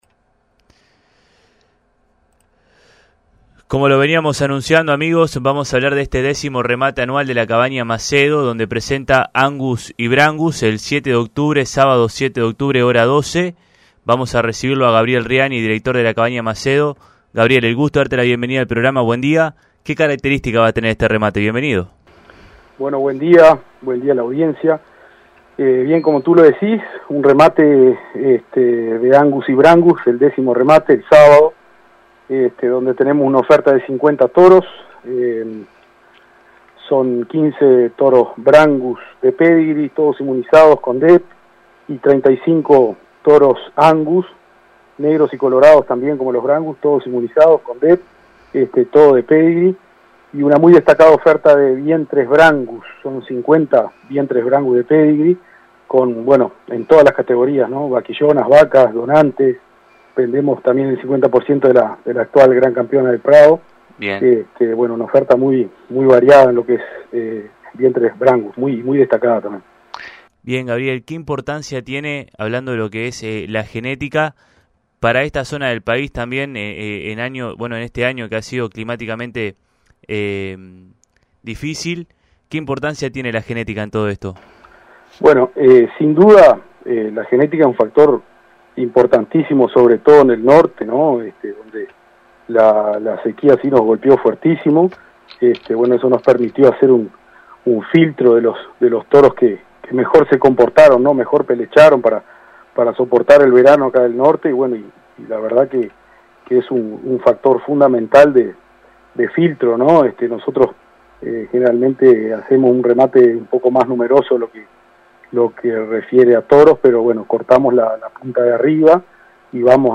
En esta entrevista